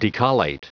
Prononciation du mot decollate en anglais (fichier audio)